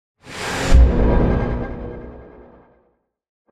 conjuration-magic-sign-rune-intro.ogg